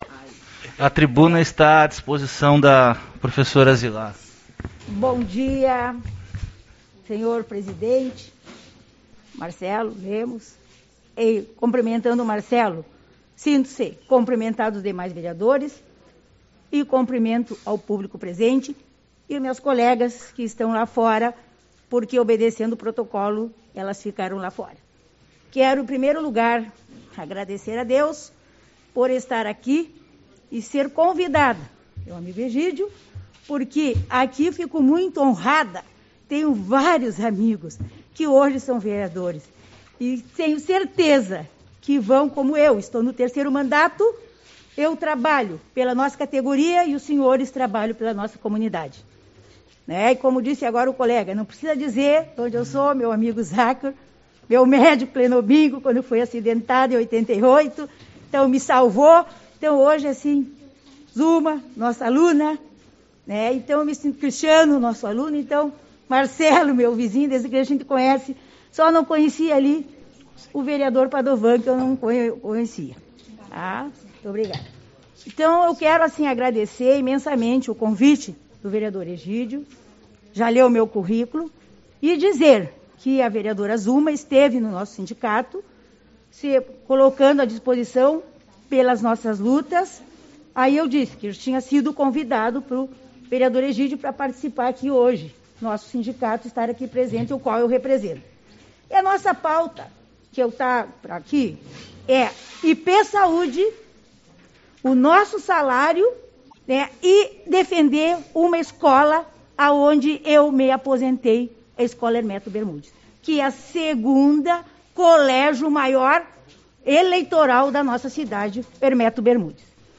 05/08 - Reunião Ordinária